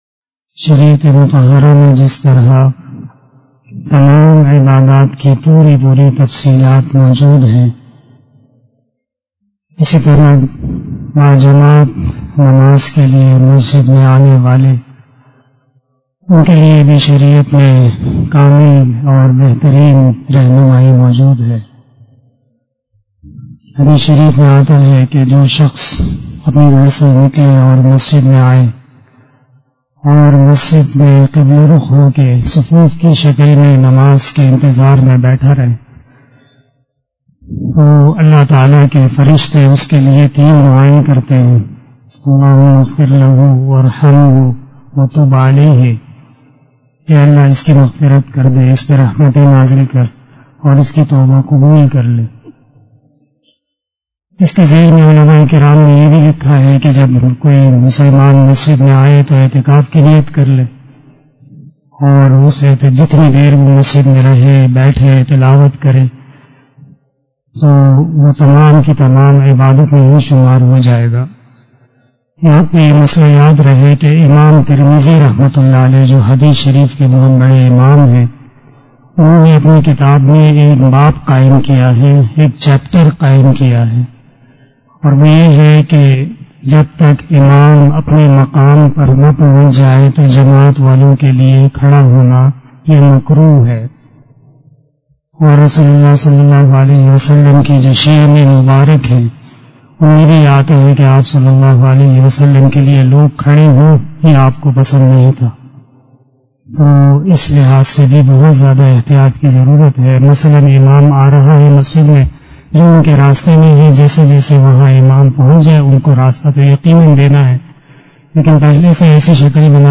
027 After Asar Namaz Bayan 15 June 2021 ( 04 Zulqadah 1442HJ) tuesday